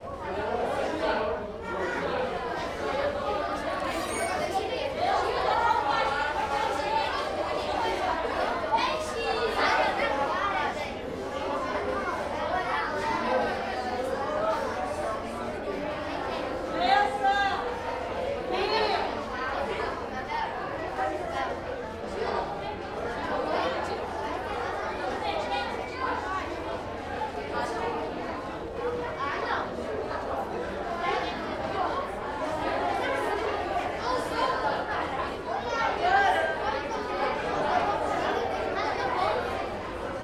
Um biblioteca digital com 2000 sons originais do Cerrado, 100% GRATUITOS para seus projetos audiovisuais.
CSC-04-016-LE - Ambiencia sala vazia camara dos deputados pessoas conversando longe.wav